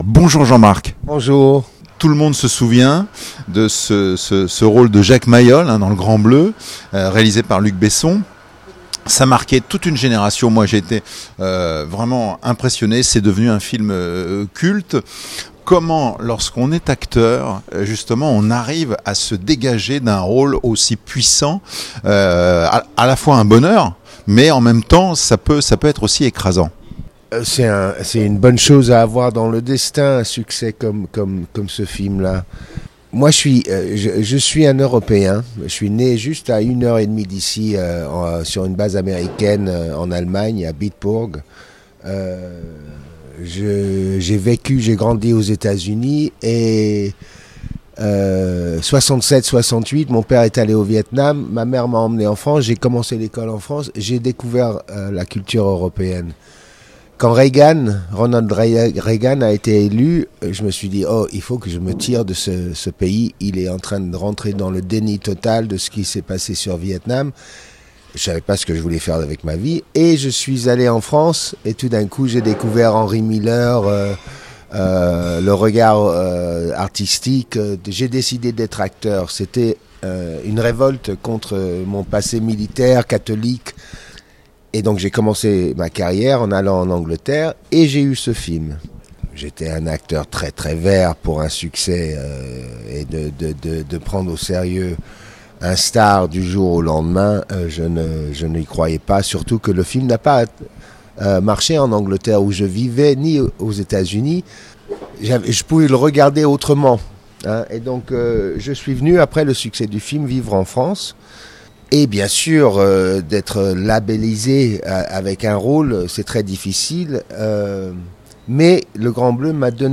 Invité surprise de la 10e édition du Festival International du Film de Comédie de Liège, Jean-Marc Barr a rejoint sur scène Jean Reno, son complice du Grand Bleu.